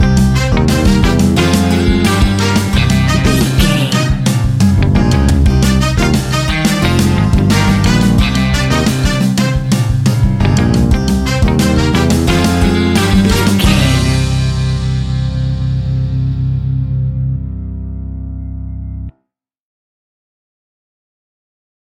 Aeolian/Minor
latin